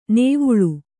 ♪ nēvuḷu